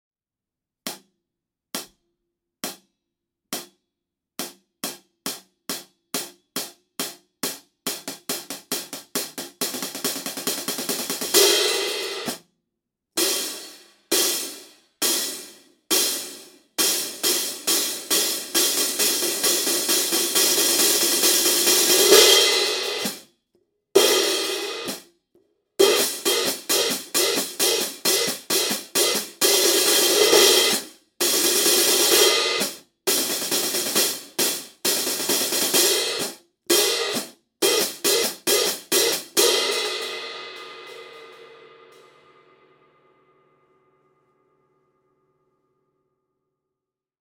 Heartbeat Raw Hi-hat Cymbals: Dark, meaty and sloshy sounding.
Overtones are greatly reduced and the decay is very quick.
Hats are meaty and sloshy sounding.
Minimal lathing means minimal shimmer.
17″ Raw Light Hi-hat Cymbals: Approx 2770 Grams Combined.
17_-Raw-Light-Hi-Hats.mp3